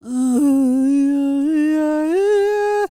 E-CROON 3037.wav